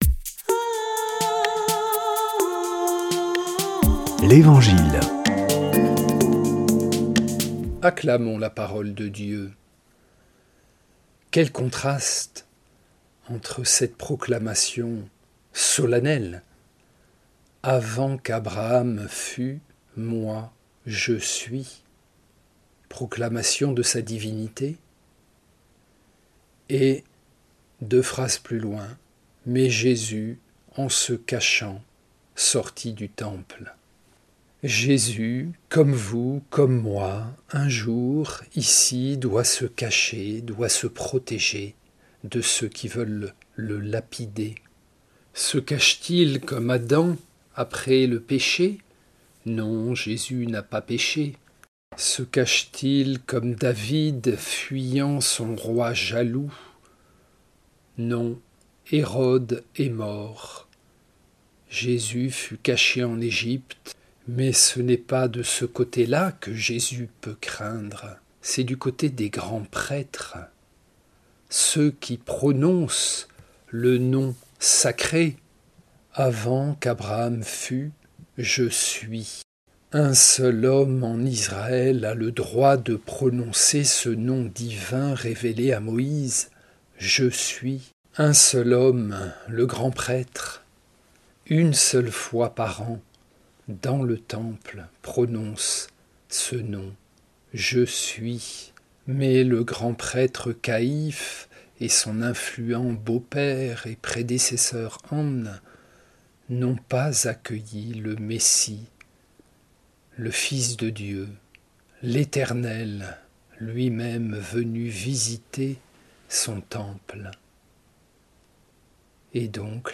Des prêtres de la région